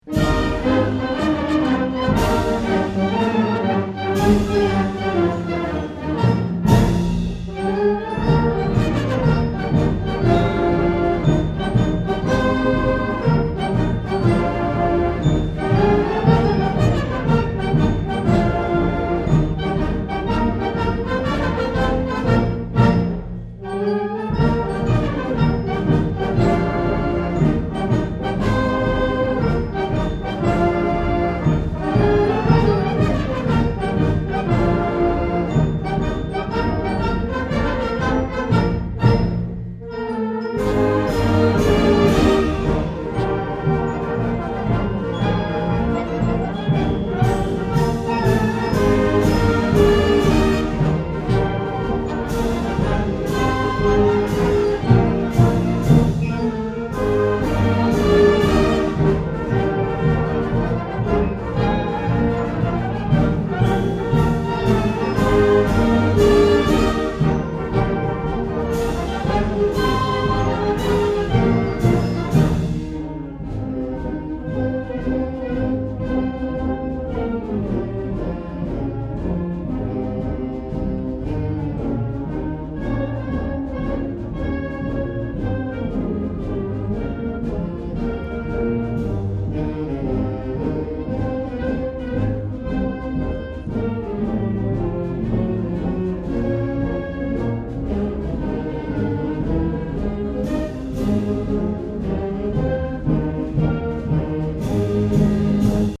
2008 Winter Concert
December 14, 2008 - San Marcos High School